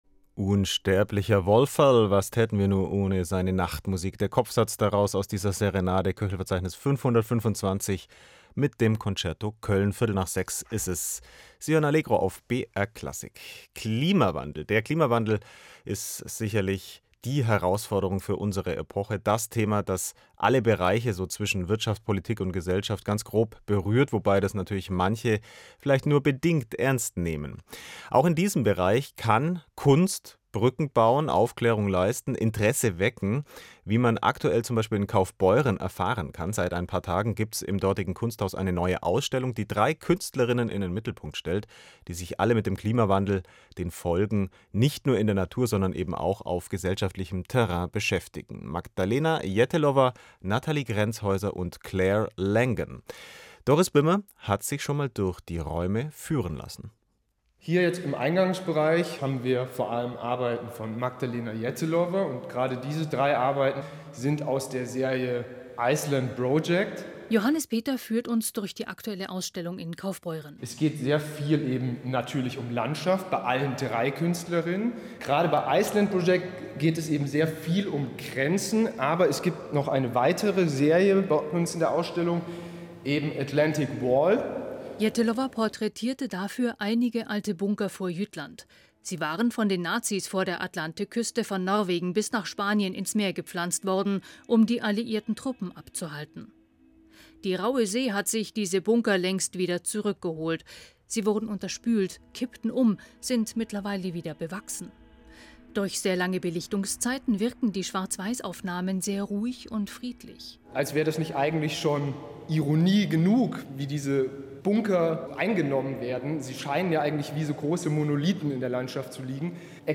Ausstellung „Terra Infirma“ im Kunsthaus Kaufbeuren | Bericht